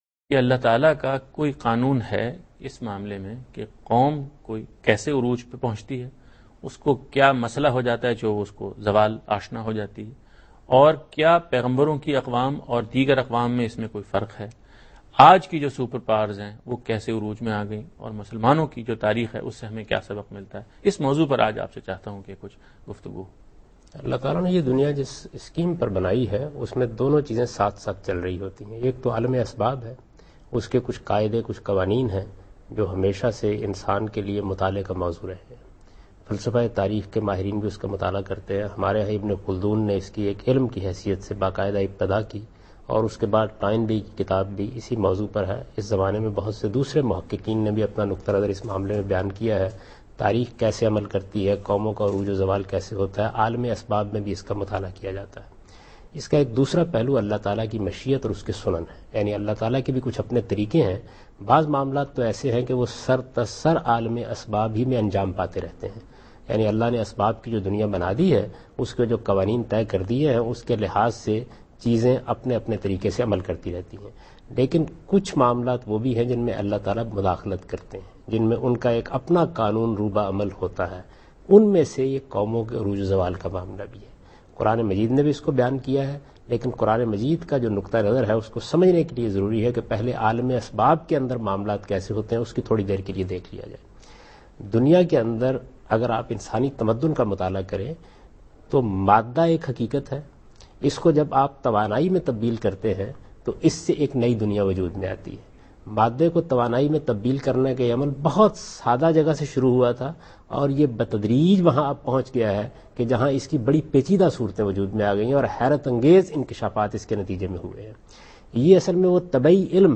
Category: TV Programs / Dunya News / Deen-o-Daanish /
Javed Ahmad Ghamidi answers a question regarding "Rise and Fall of Nations" in program Deen o Daanish on Dunya News.
جاوید احمد غامدی دنیا نیوز کے پروگرام دین و دانش میں قوموں کے عروج و زوال سے متعلق ایک سوال کا جواب دے رہے ہیں۔